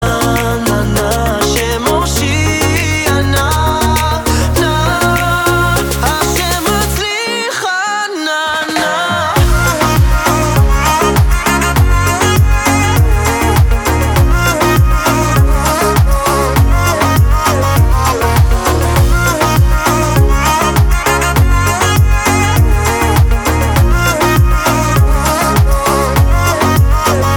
במנגינה רגועה